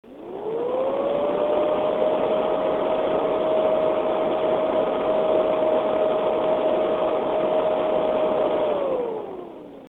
Acoustic Samples: Listen to this Heatsink!
With the Evercool K802-715CA spinning at a glorious 3500 revolutions per minute, noise levels are noticeable but not ear splitting.
FrostyTech Acoustic Sampling Chamber
High-Speed Recording.
The heatsink is moderately noisy, but not so much so that it will be completely distracting outside the case in an office environment.